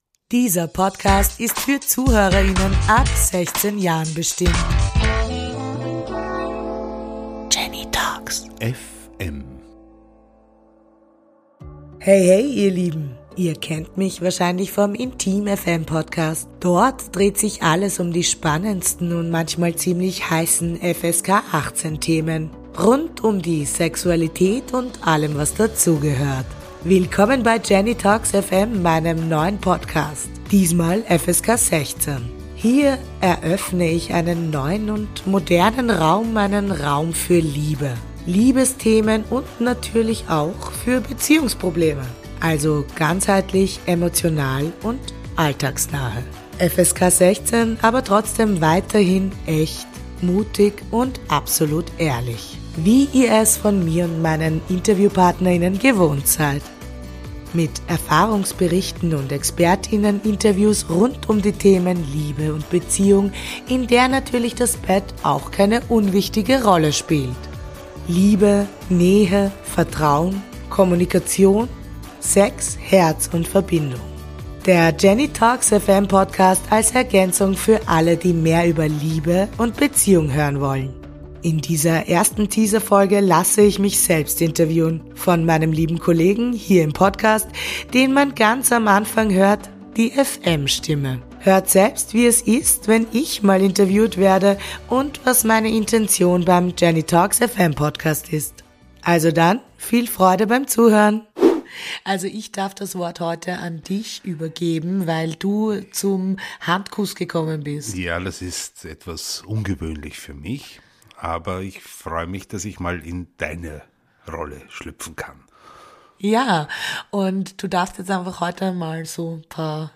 Der Teaser